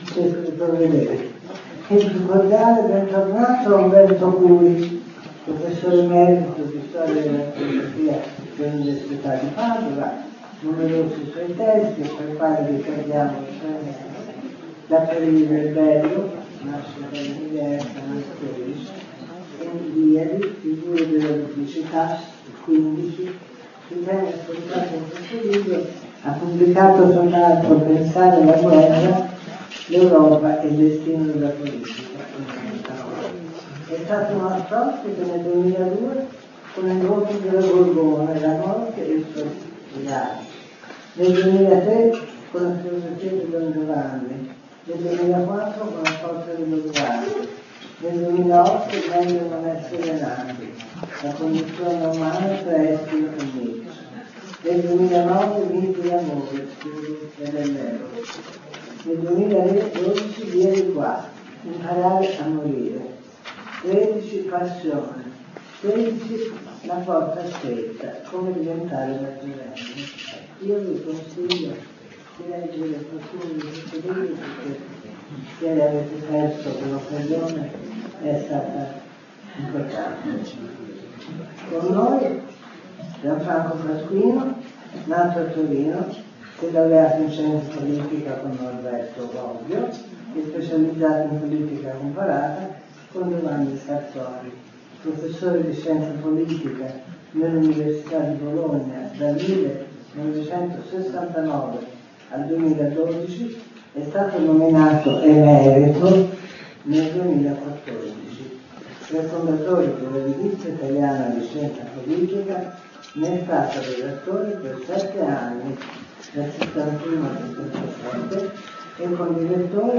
Gianfranco Pasquino Molto numeroso il pubblico presente Video Audio